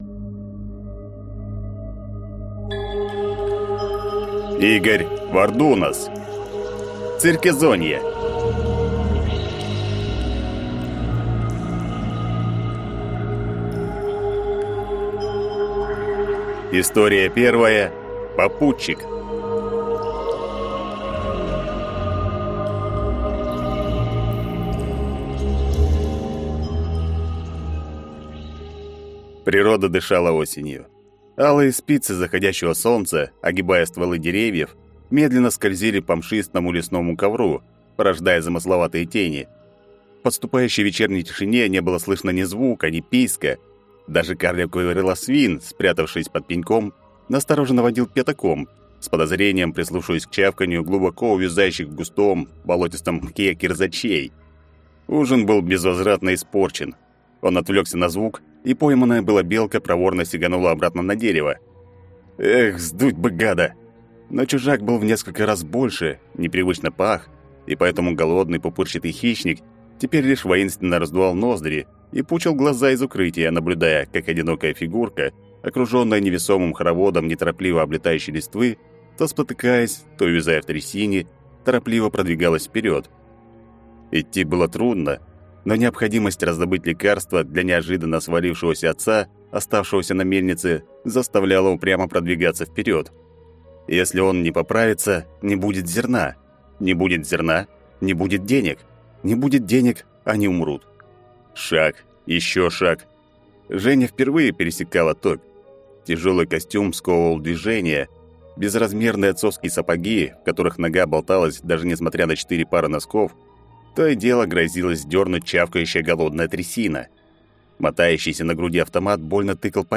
Аудиокнига Циркезонье | Библиотека аудиокниг